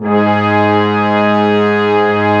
55m-orc08-G#2.wav